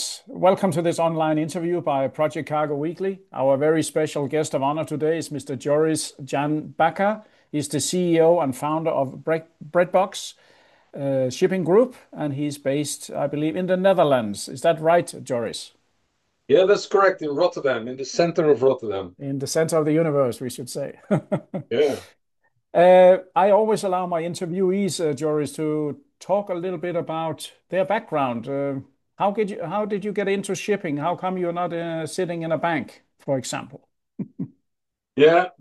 Breabbox Shipping Interview